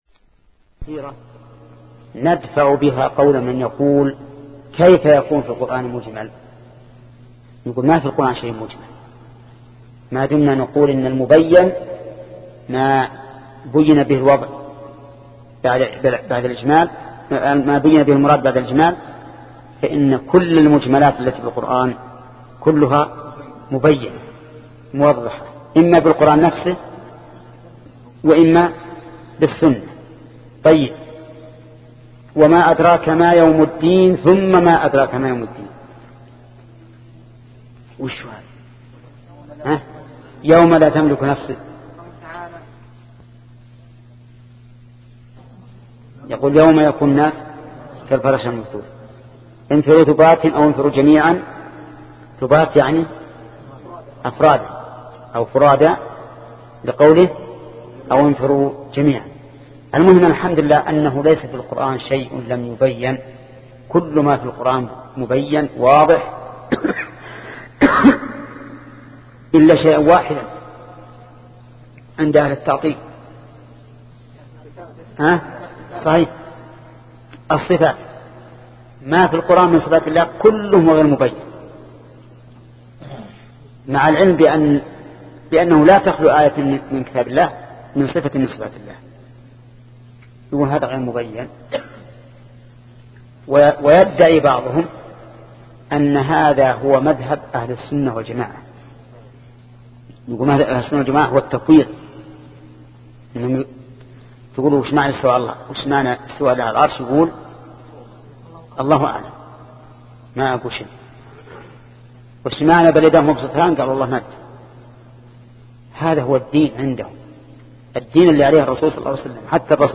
شبكة المعرفة الإسلامية | الدروس | الأصول من علم الأصول 11 |محمد بن صالح العثيمين